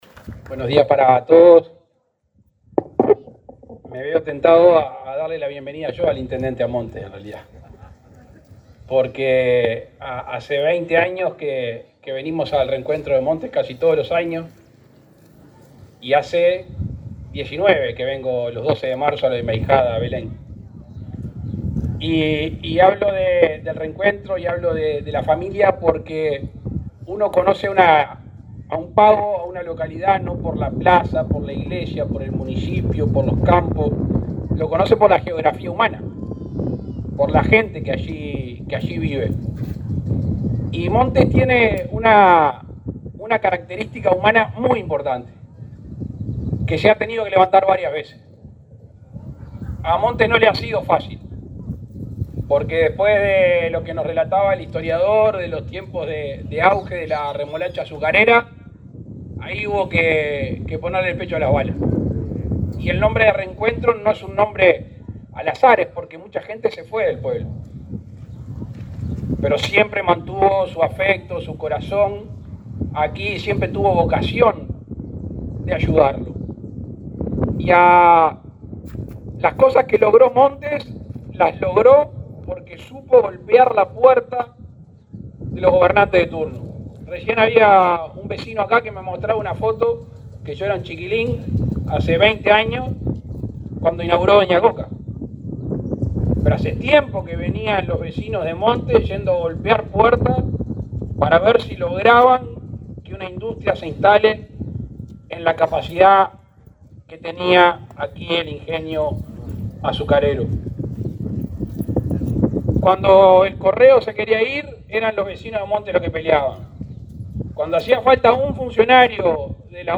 Palabras del presidente Luis Lacalle Pou
El presidente Luis Lacalle Pou encabezó este viernes 12 la celebración por los 130 años de la localidad de Montes, Canelones.